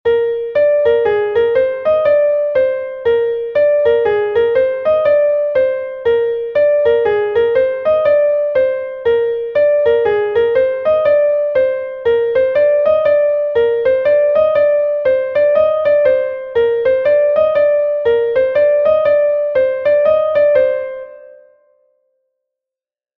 a Laridé from Brittany